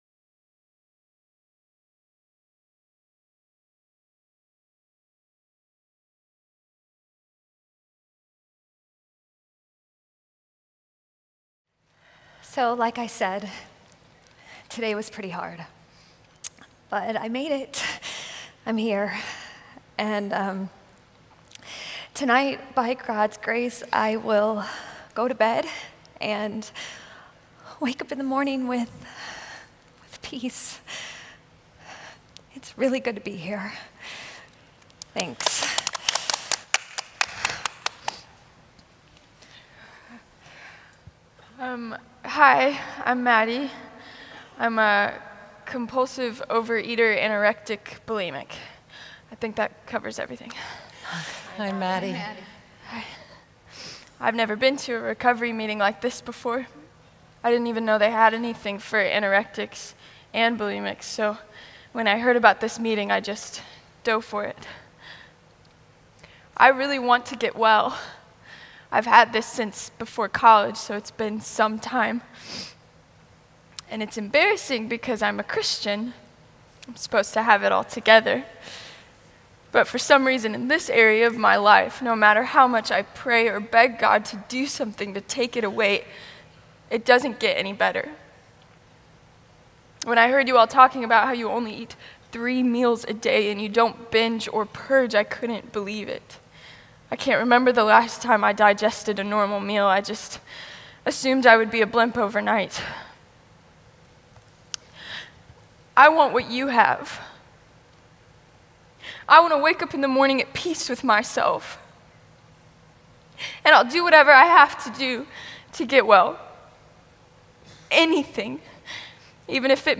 Message 7 (Drama): Healing Indulgent Excess